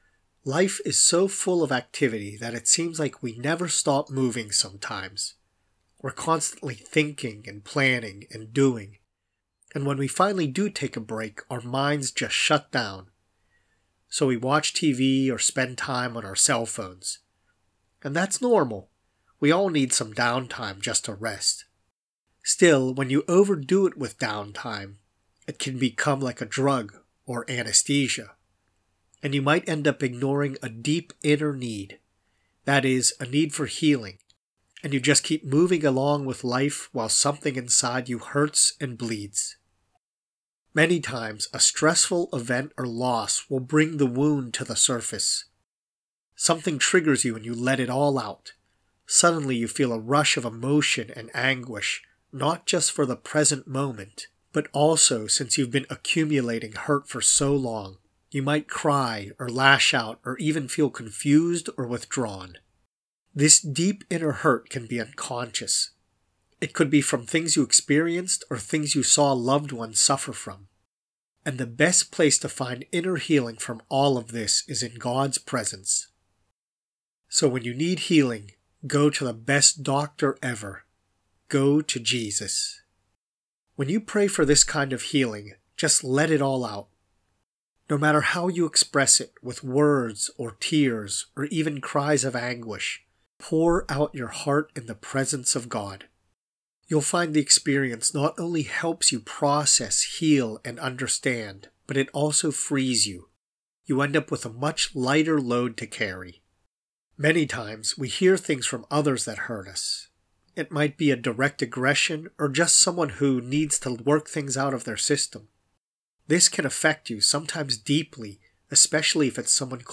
prayer-for-inner-healing.mp3